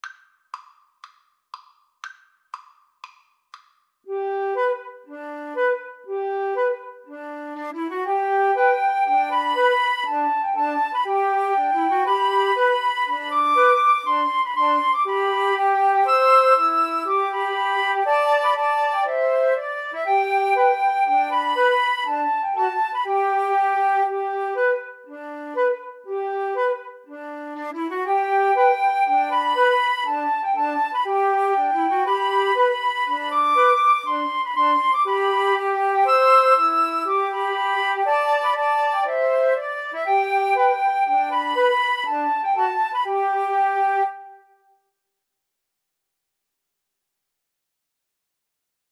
G major (Sounding Pitch) (View more G major Music for Flute Trio )
Moderately Fast
Traditional (View more Traditional Flute Trio Music)